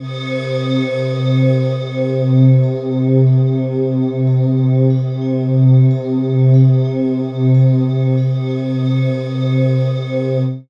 36ak02pad1cM.wav